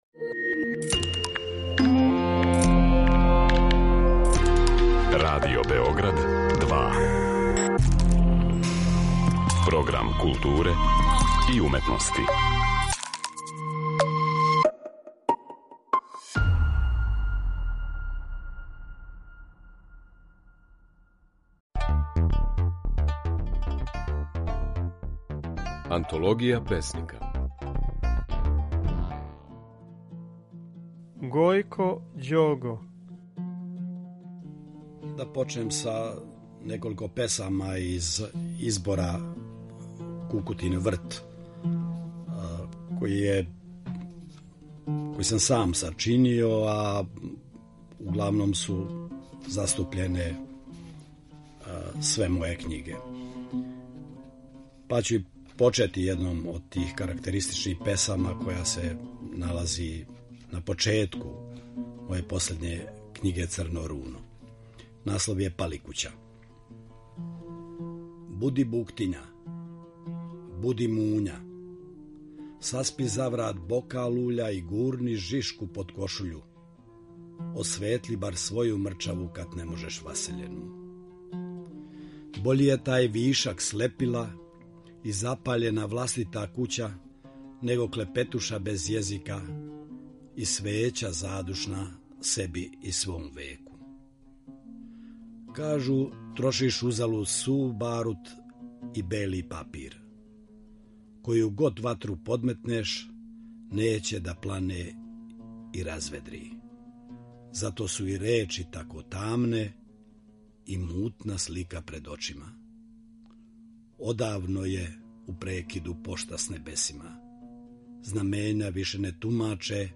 Песник Гојко Ђого говориће своју поезију
Емитујемо снимке на којима своје стихове говоре наши познати песници